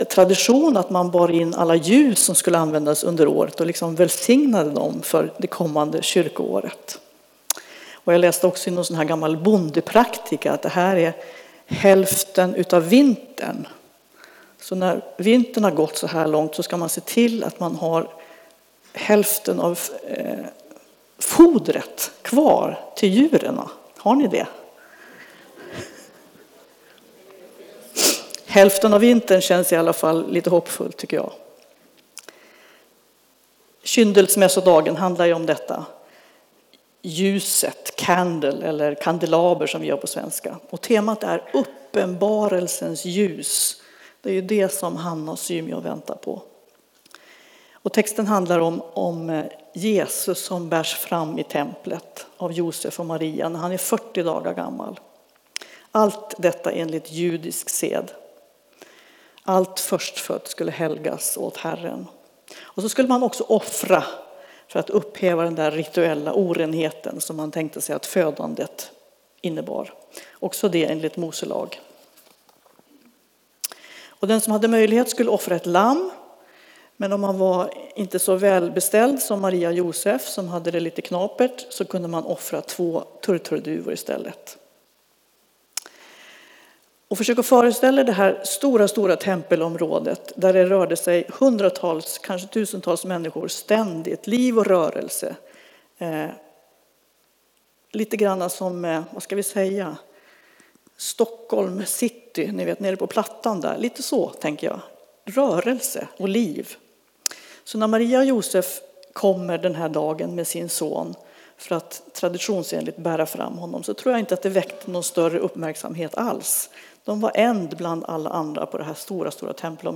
Gudstjänst 2 februari